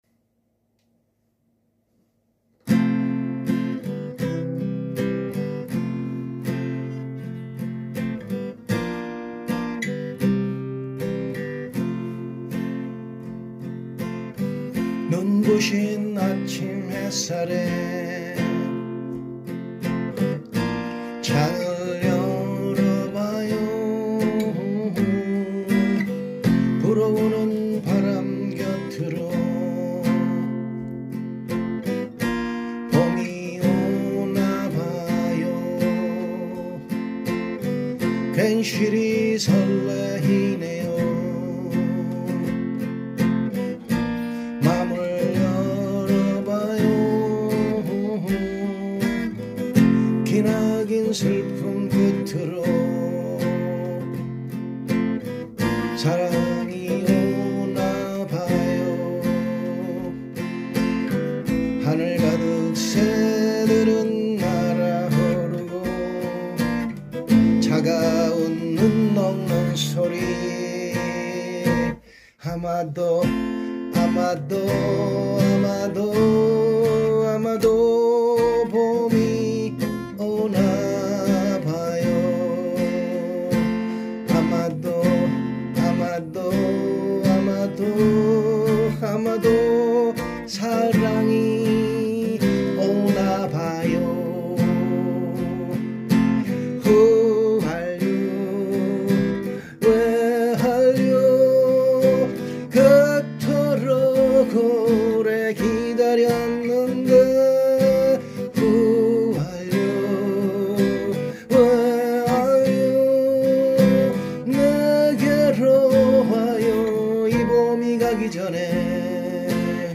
따라서 보다 안정적인 음역에서 연주와 보컬을 동시에 하기 위해 F Major로 키를 조정하였습니다.
F – C – F – Bb – C – F
아래는 기타 반주에 맞춰 직접 연주하고 노래한 간단한 녹음입니다.
기타 연주 및 노래